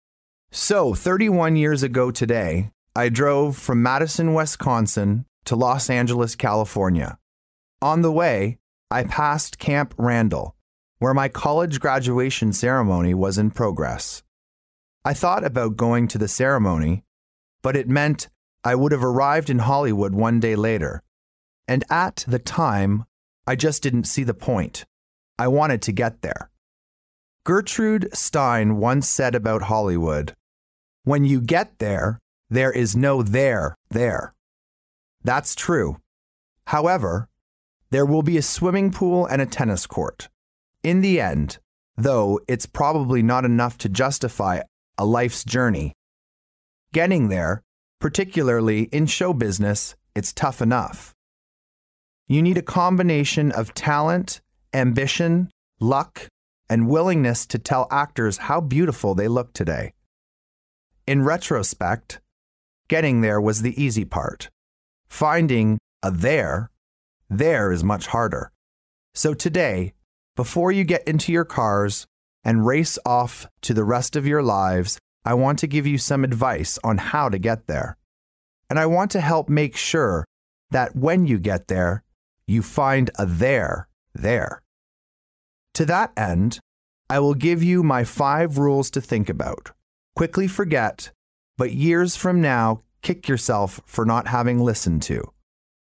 名校励志英语演讲 88:如何实现你的梦想 听力文件下载—在线英语听力室
借音频听演讲，感受现场的气氛，聆听名人之声，感悟世界级人物送给大学毕业生的成功忠告。